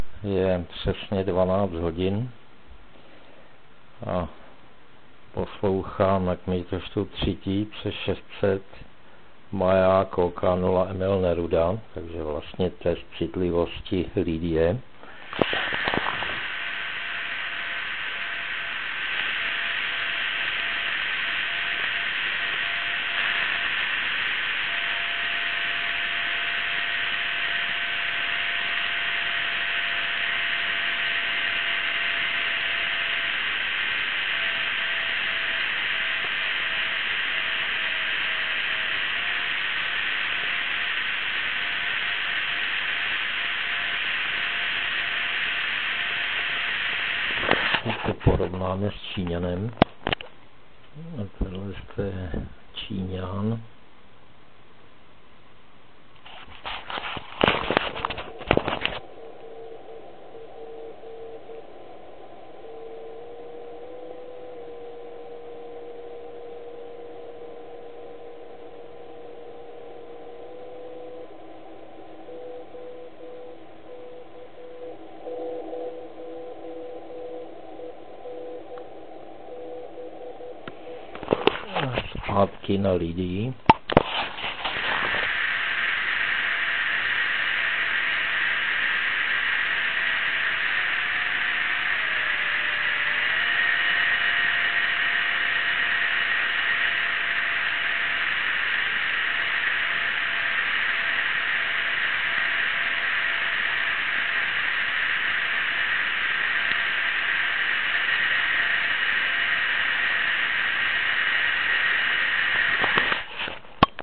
V poledních hodinách jsem poslouchal maják OK0EN s výkonem 150mW.
Lidia_test_citlivosti.mp3